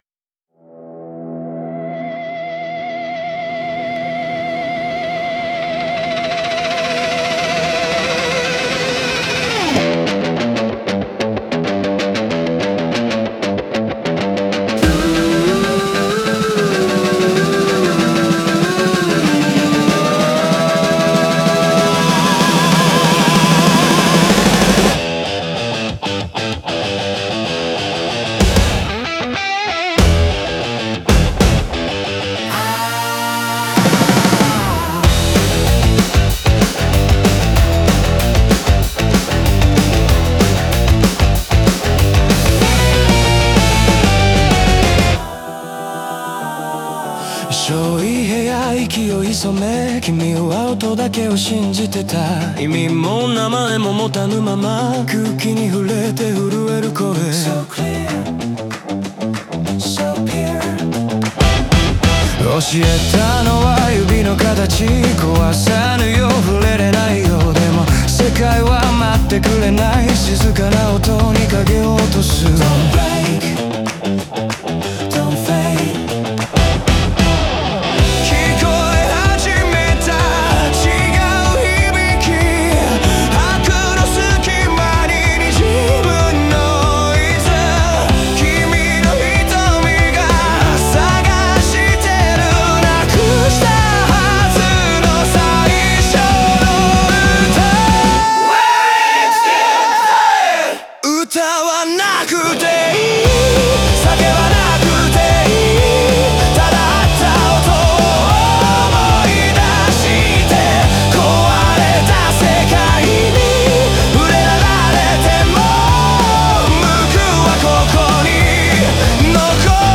中盤に進むにつれ、リズムや音の密度が増し、外界からの圧力やノイズが重なっていく構成となっている。